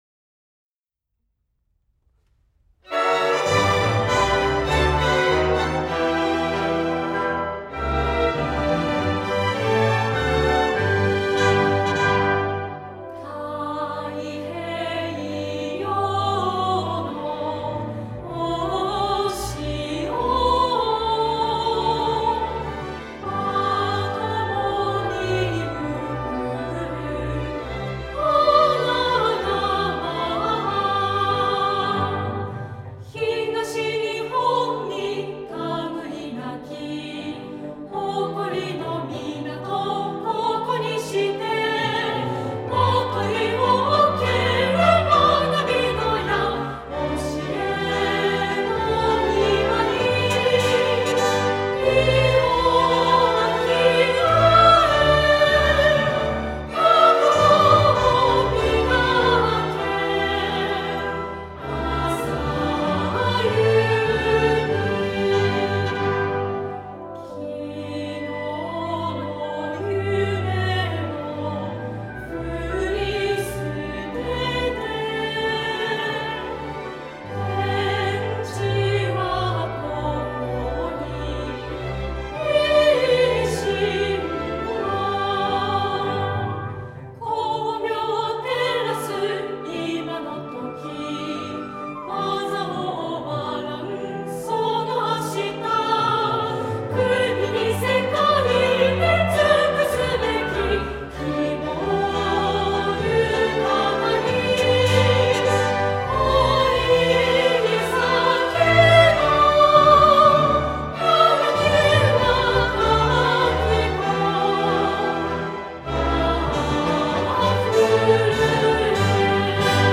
■合唱付き